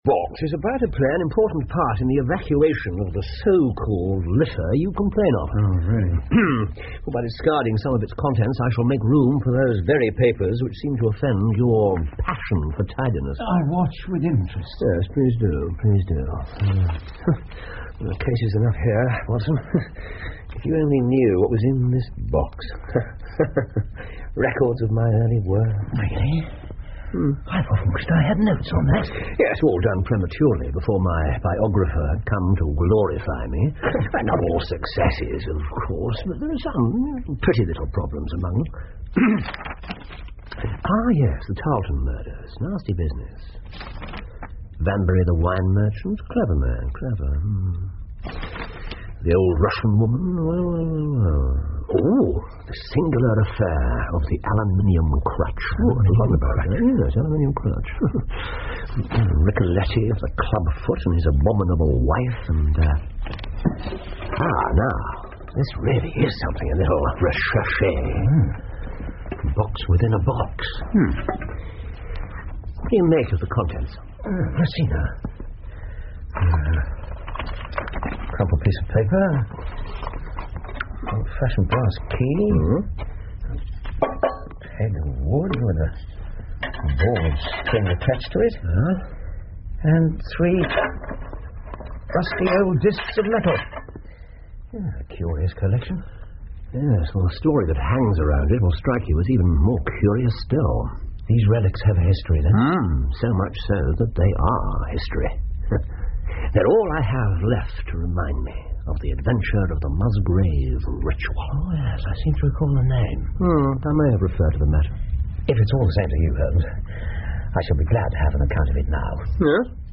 福尔摩斯广播剧 The Musgrave Ritual 2 听力文件下载—在线英语听力室